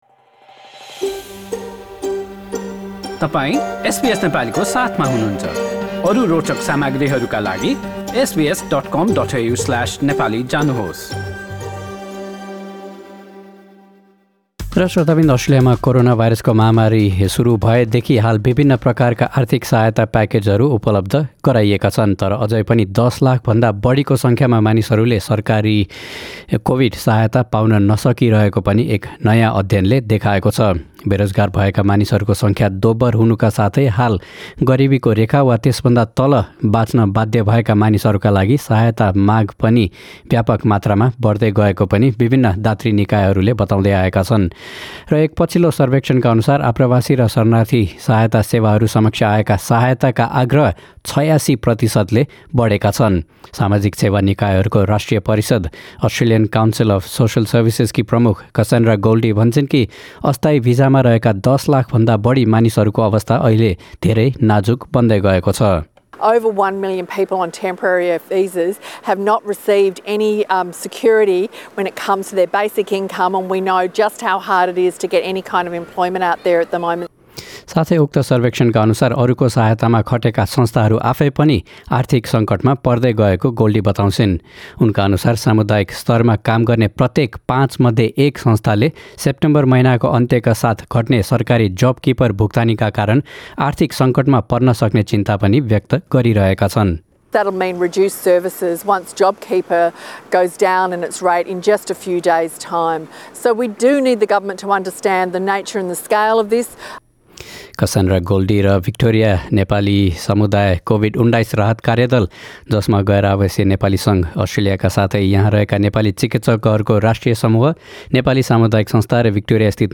A text version of this news report is available in the Nepali language version of our website.